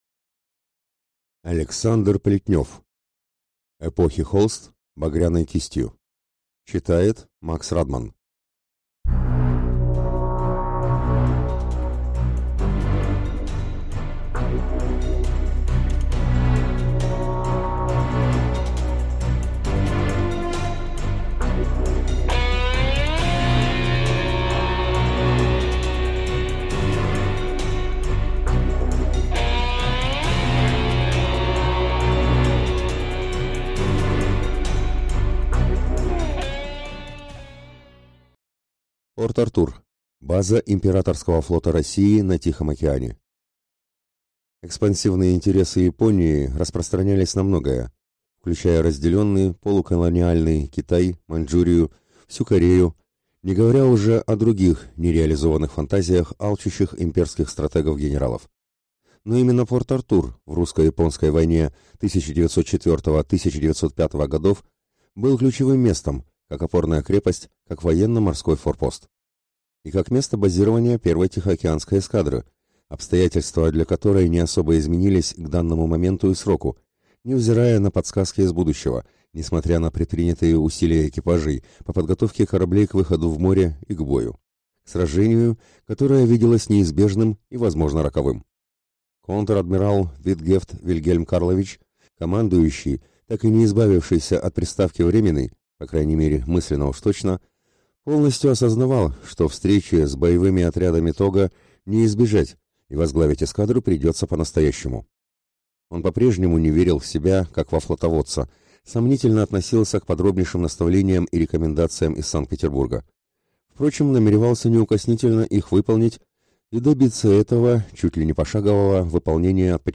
Аудиокнига Эпохи холст – багряной кистью | Библиотека аудиокниг